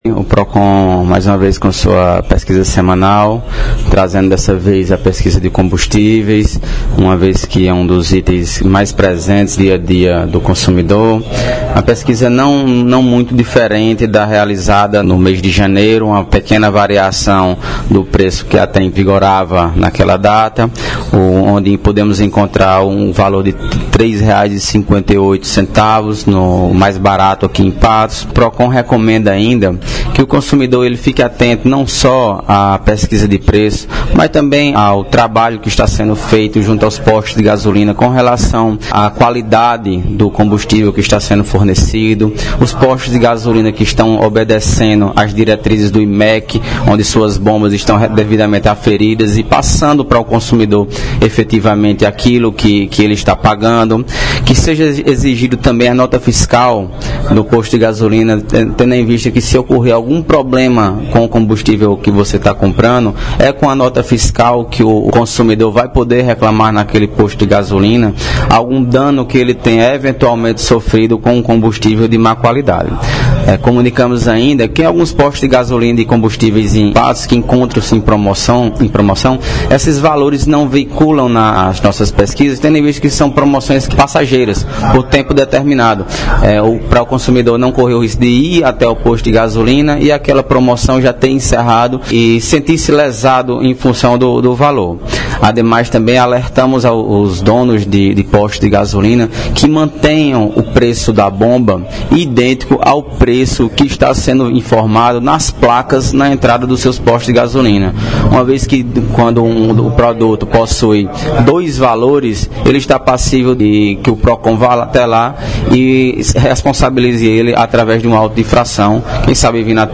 Fala do secretário do PROCON – Bruno Maia –